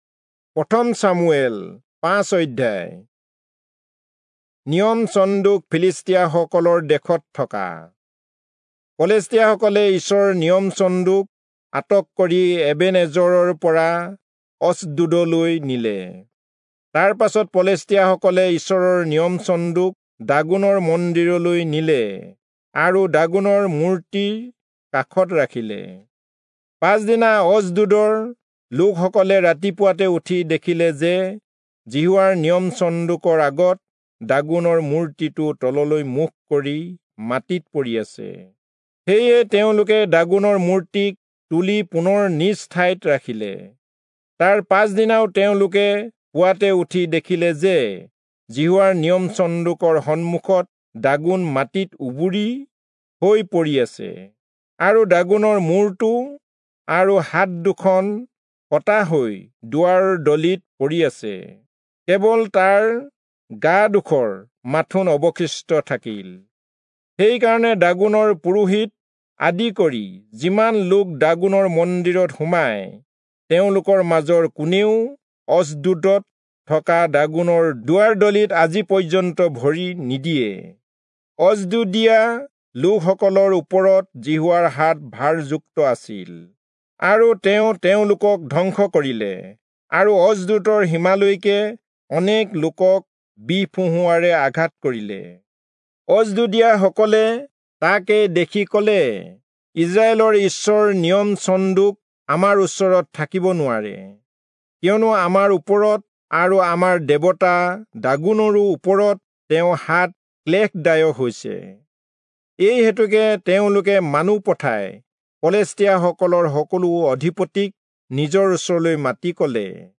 Assamese Audio Bible - 1-Samuel 7 in Mov bible version